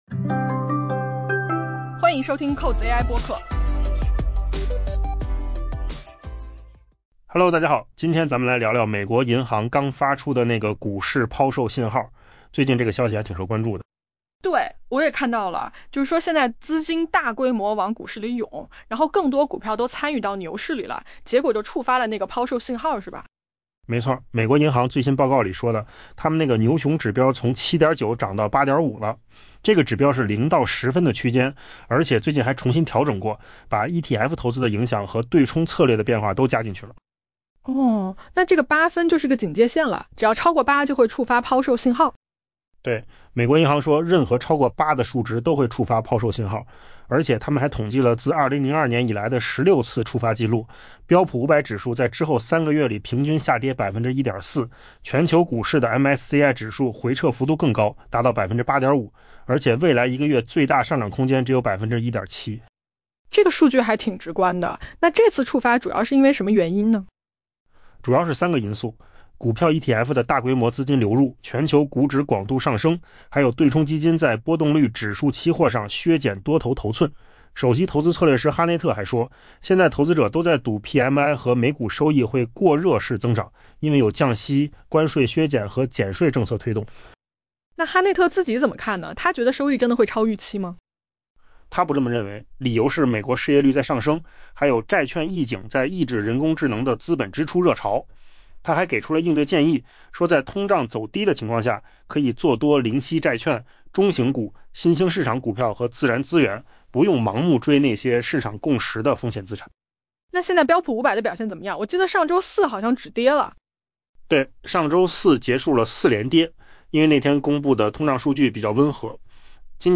音频由扣子空间生成 下载mp3
AI播客：换个方式听新闻